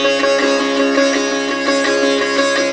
SITAR GRV 19.wav